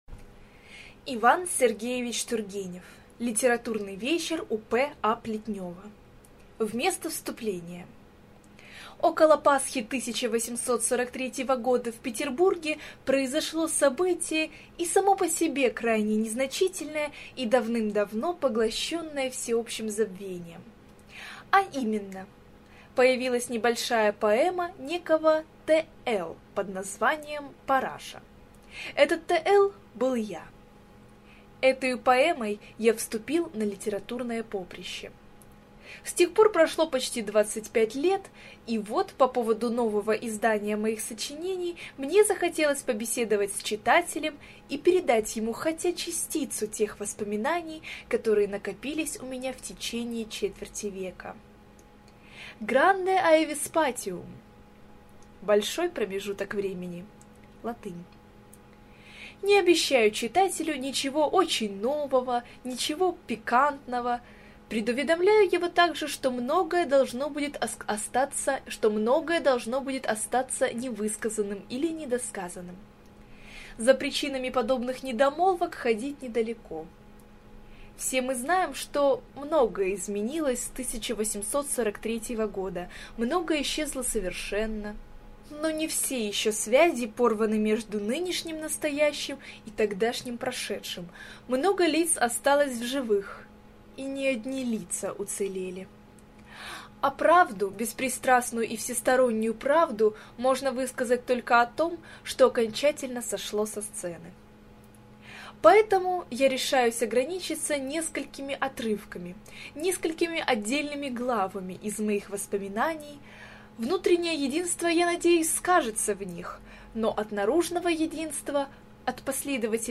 Аудиокнига Литературный вечер у П.А. Плетнева | Библиотека аудиокниг